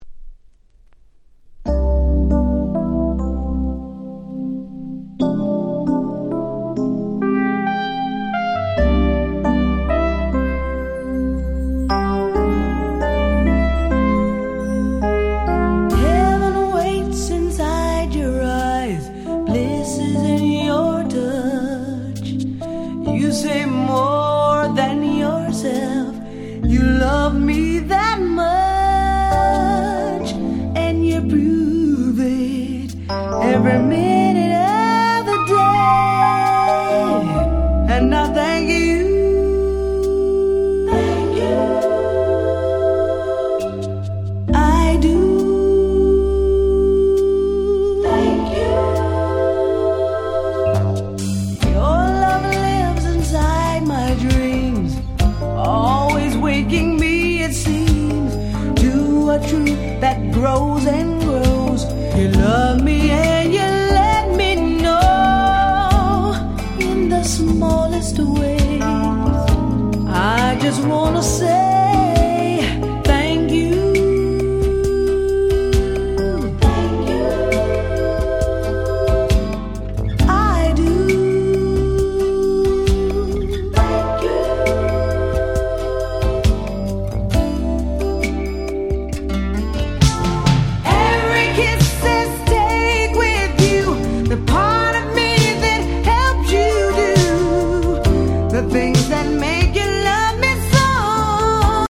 87' Nice R&B Album !!
80's R&B